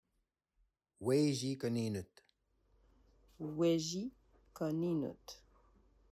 Weci | Koninut, (/we.ʒi kɔ.ni.nut/) est un mot rêvé par les créateurs pour désigner un capteur de rêves.